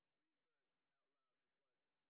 sp04_street_snr10.wav